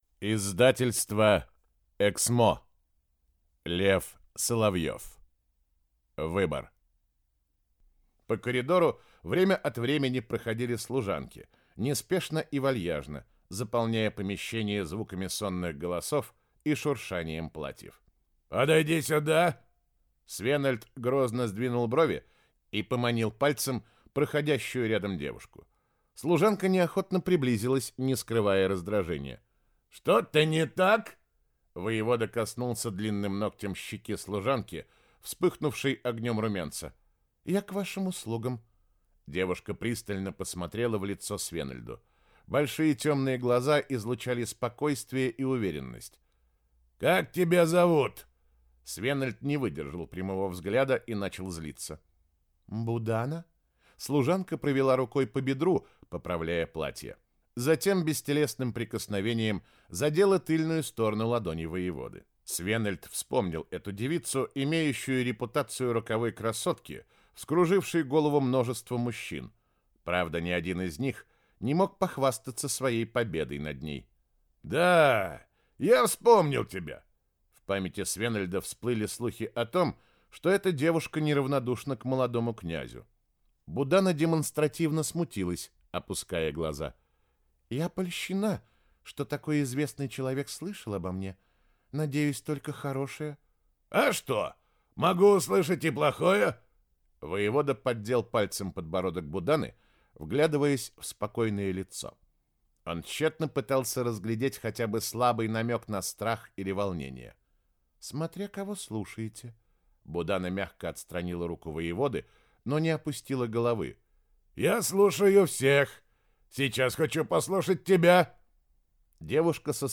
Аудиокнига Выбор | Библиотека аудиокниг